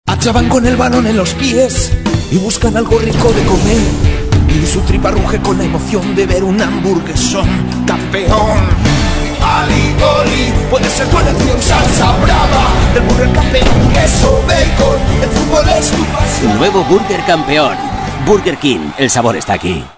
Anuncios / Tonos Divertidos
al estilo de los dibujos animados